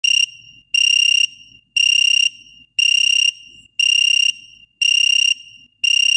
звук задержки.mp3